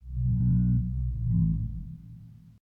livingcavesmobs_explodingbacteria3.ogg